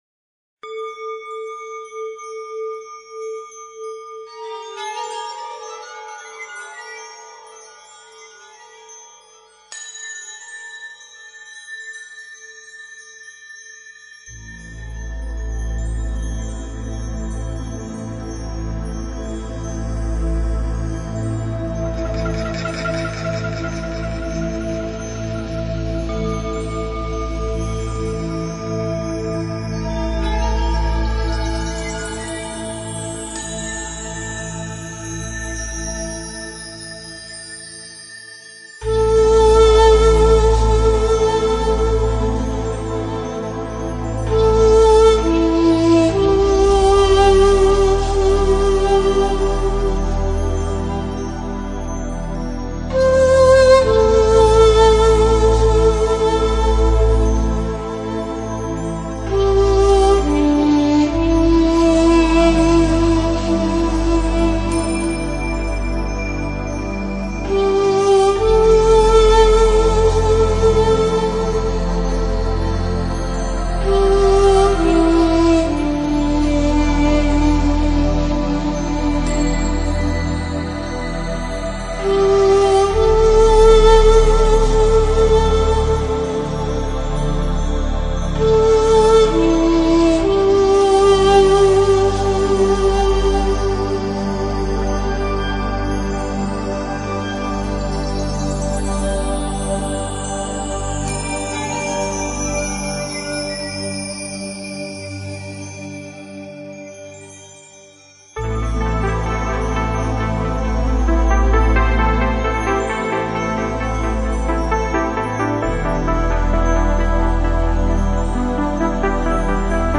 以排箫为主调
排箫、钢琴、键盘和打击乐器的结合，巧妙的营造出一种富有节奏感的韵律。既不同于舞曲的强劲，却又具有欢快、鲜明的节奏